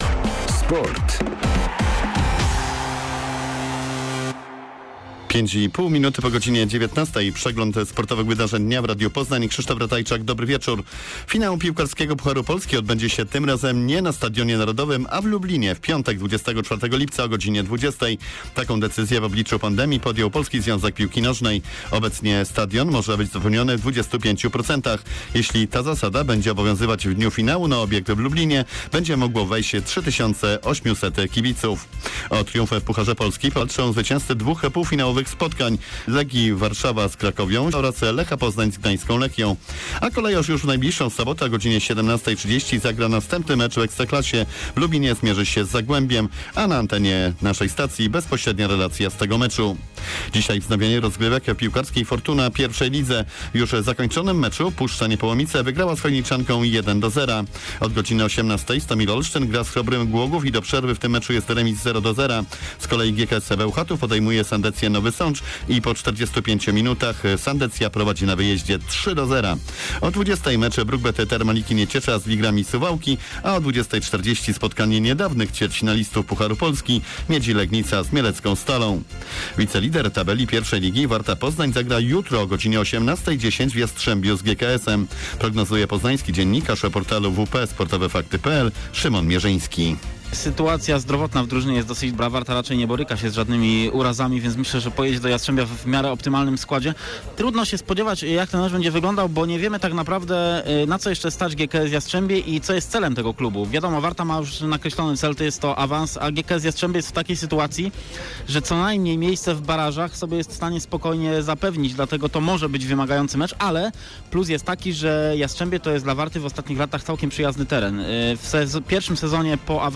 02.06. SERWIS SPORTOWY GODZ. 19:05